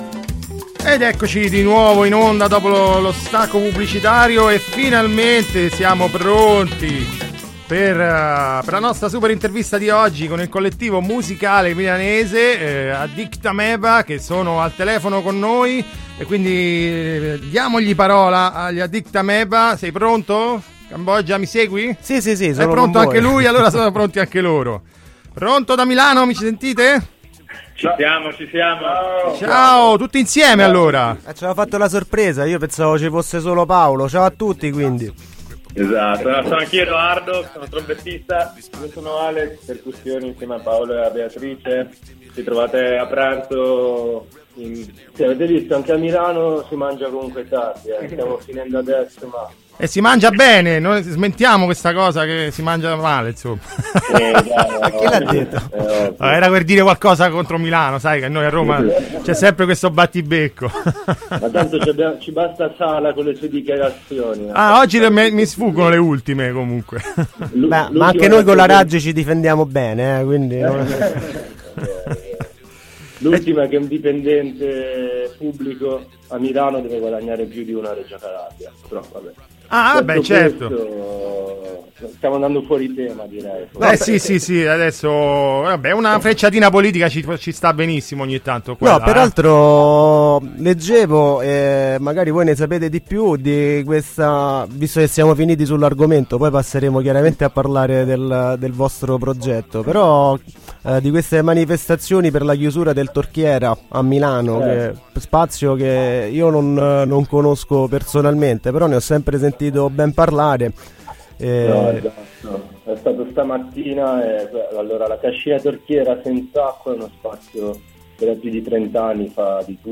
groovytimes-intervista-addictameba.mp3